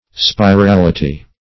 Spirality \Spi*ral"i*ty\, n.
spirality.mp3